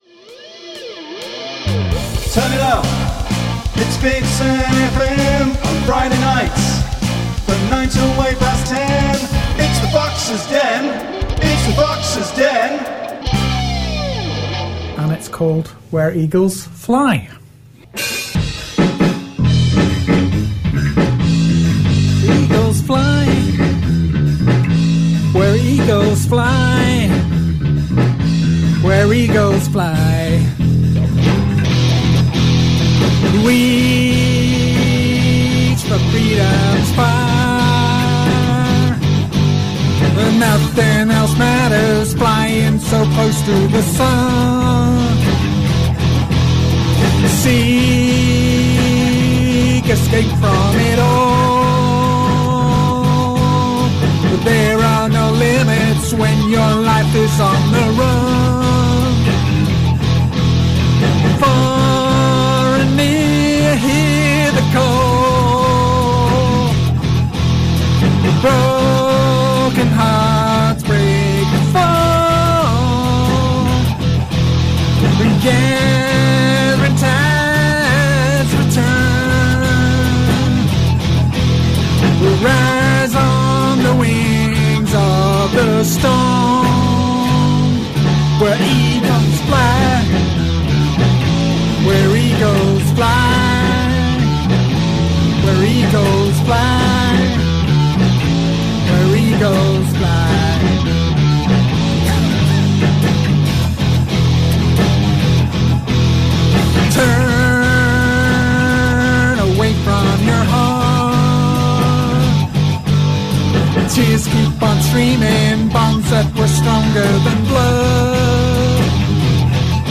Live Recordings and Radio Sessions.